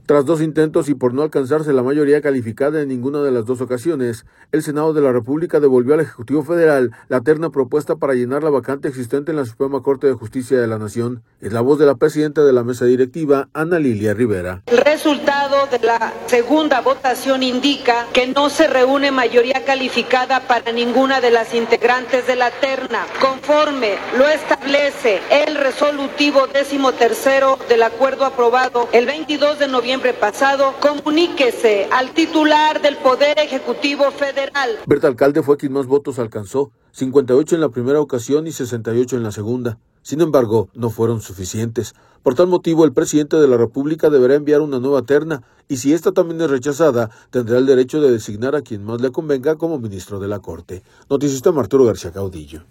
Es la voz de la presidenta de la Mesa Directiva, Ana Lilia Rivera.